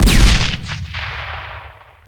smallcannon.ogg